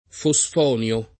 fosfonio [ fo S f 0 n L o ]